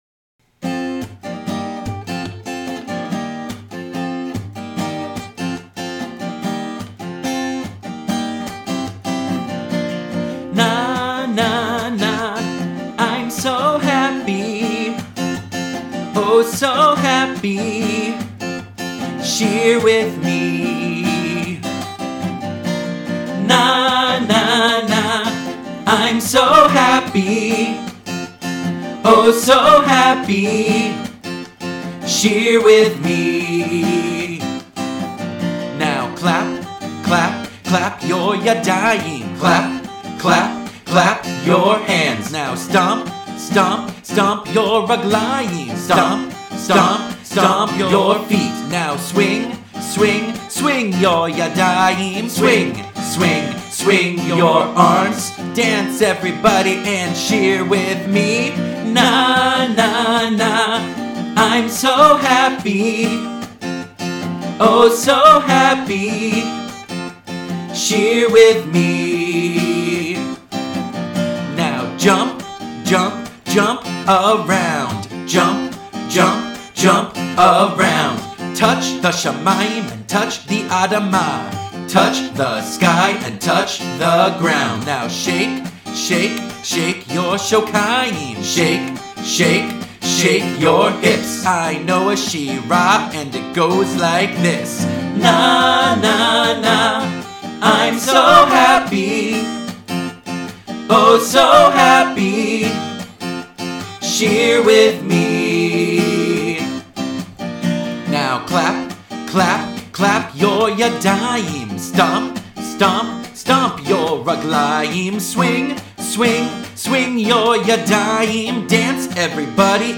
Nursery School Music